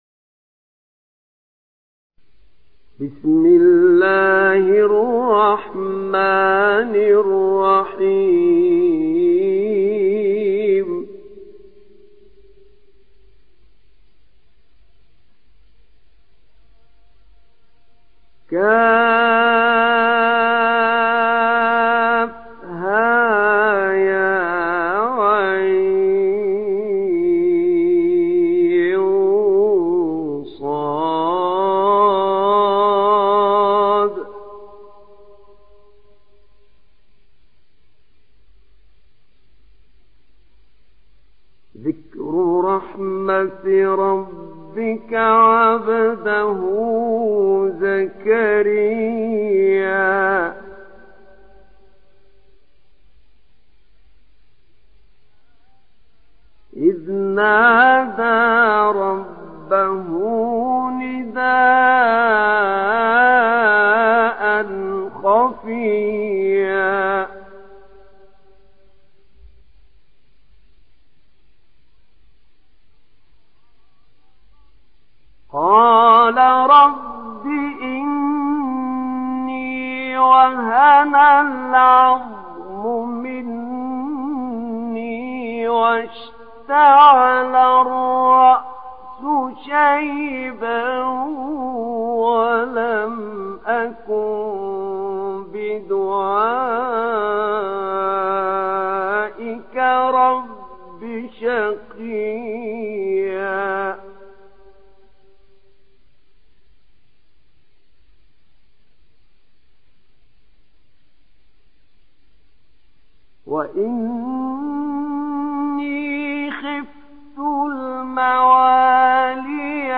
تحميل سورة مريم mp3 بصوت أحمد نعينع برواية حفص عن عاصم, تحميل استماع القرآن الكريم على الجوال mp3 كاملا بروابط مباشرة وسريعة